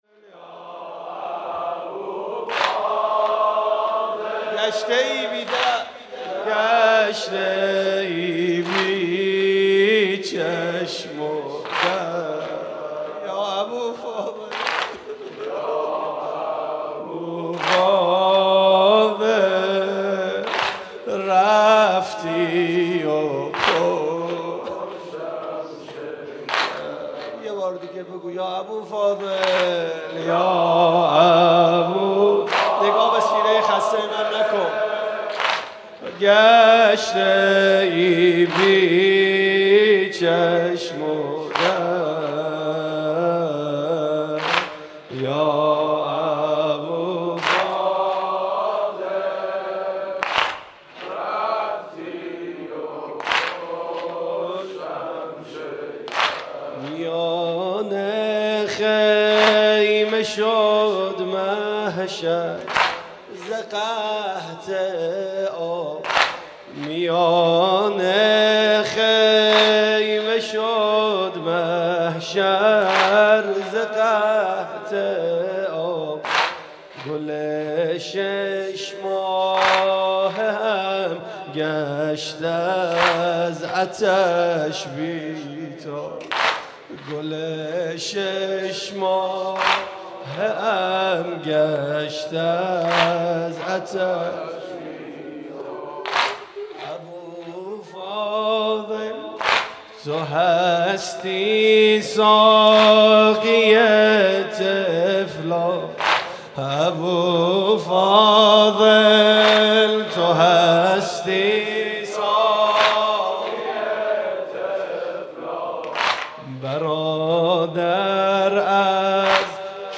گلچين محرم 95 - واحد - رفتی و پشتم شکست